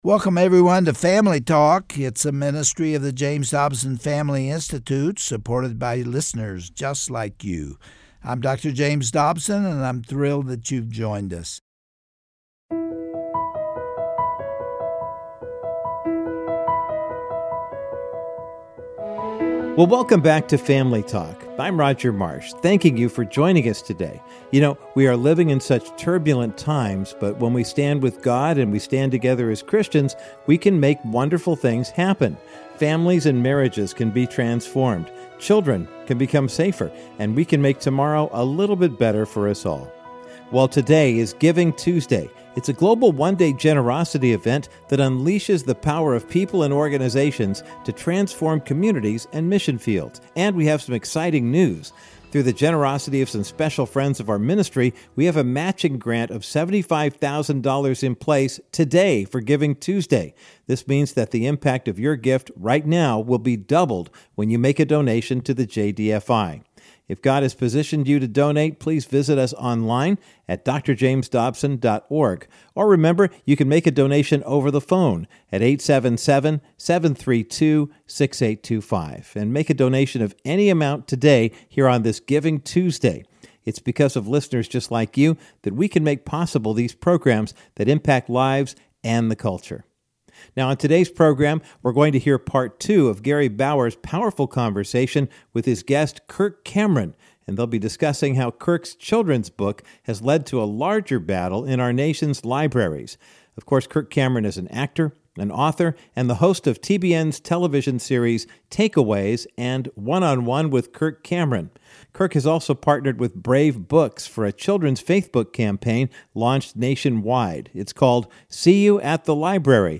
Host Gary Bauer
Guest(s):Kirk Cameron